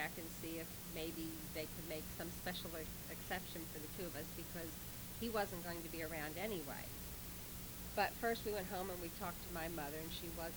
Its unprocessed and in WAV.
It’s clear to see that in the first example, the voice level is much higher than the noise level, but in the second picture the noise is almost as loud as the voice.
The problem is these are coming from old cassettes or records or audio reels.